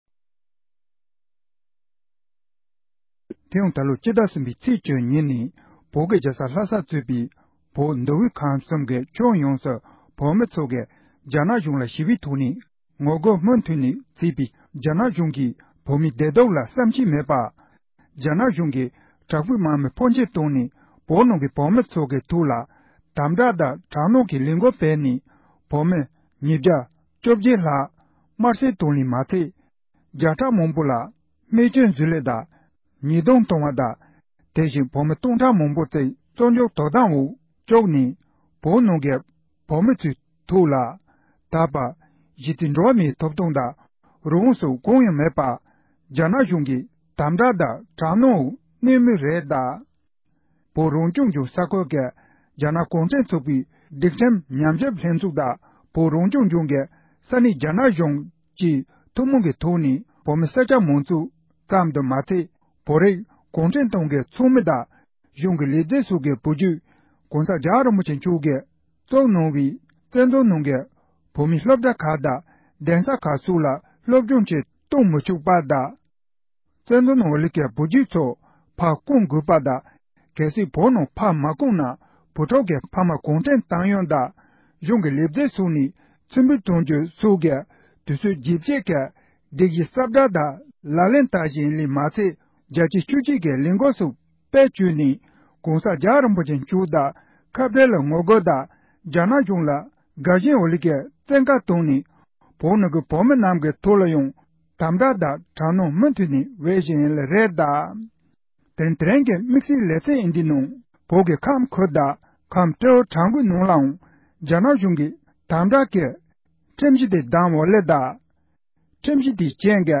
འབྲེལ་ཡོད་མི་སྣ་ལ་བཀའ་འདྲི་ཞུས་པ་ཞིག་གསན་རོགས་གནང་༎